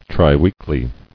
[tri·week·ly]